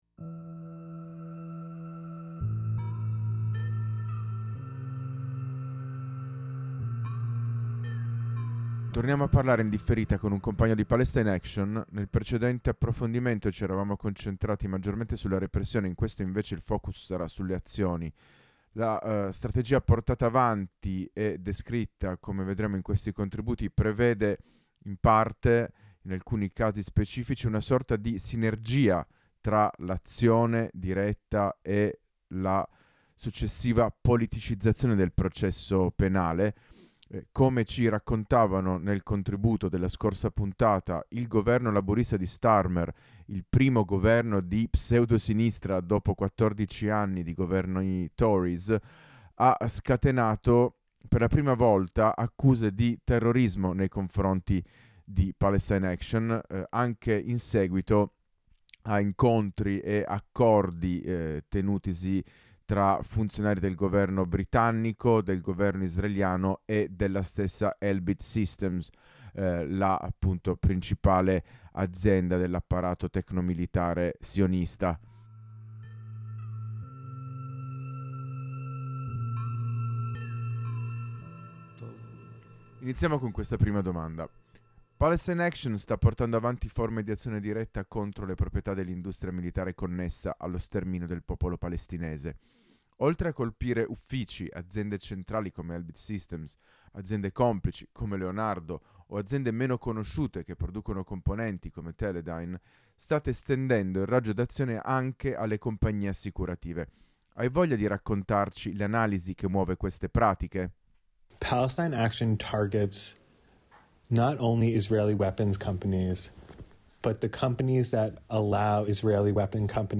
La puntata di lunedì 28 ottobre 2024 di Bello Come Una Prigione Che Brucia è andata in onda dallo studio mobile di Radio Blackout, posizionato per l’occasione – insieme alla crew dell’info e di Harraga – al Campus Einaudi dell’Università di Torino: una mattinata dedicata alla controinformazione su centri di detenzione per migranti, frontiere, guerra alle persone migranti, industria bellica, imperialismo e genocidio in Palestina.
Torniamo a parlare (in differita) con un compagno di Palestine Action.